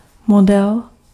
Ääntäminen
Synonyymit etalagepop ledenpop Ääntäminen Haettu sana löytyi näillä lähdekielillä: hollanti Käännös Ääninäyte 1. model {m} 2. manekýn {m} 3. manekýna {f} 4. manekýnka {f} 5. modelka {f} Suku: m .